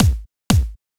Session 14 - Kick.wav